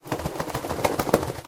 sounds / mob / wolf / shake.mp3
shake.mp3